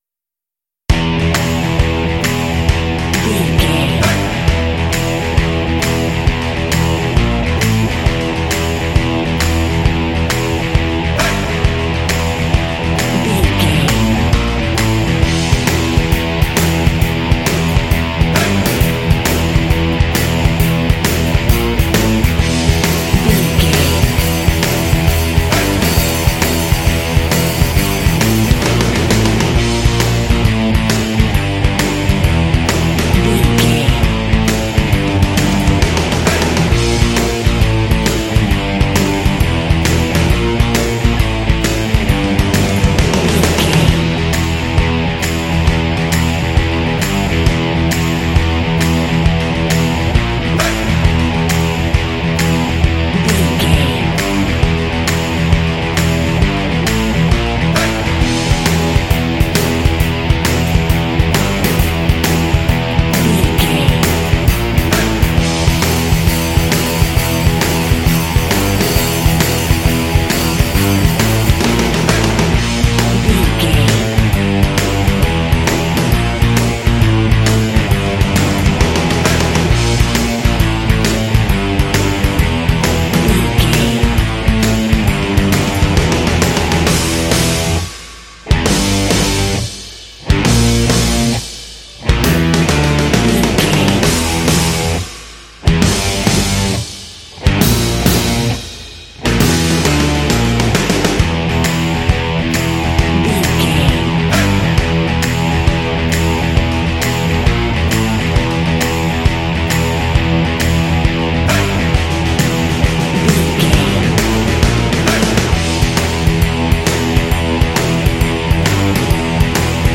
Aeolian/Minor
funky
groovy
energetic
bass guitar
electric guitar
percussion
alternative rock